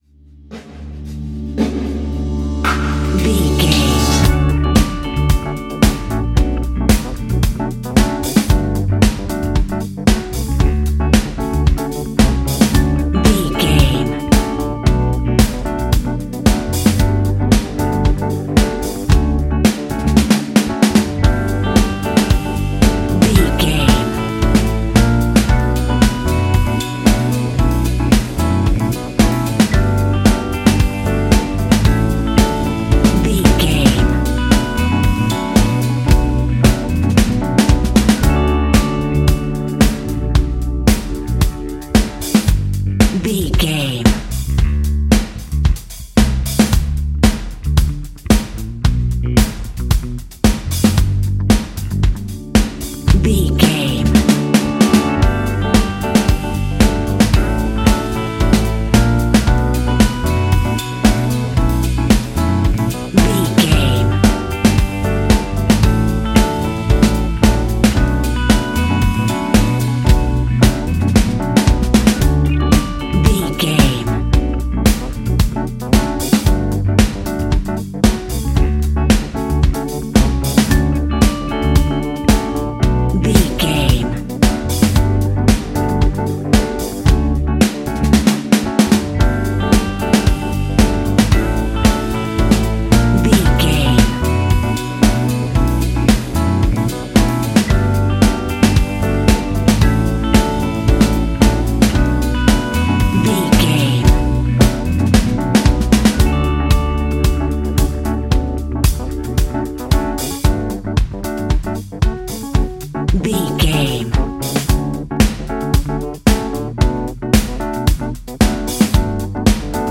Ionian/Major
D♭
house
electro dance
synths
techno
trance
instrumentals